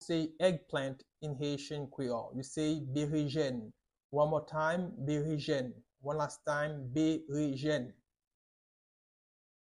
Pronunciation:
9.how-to-say-eggplant-in-Haitian-creole-–-Berejen-with-pronunciation-1-1.mp3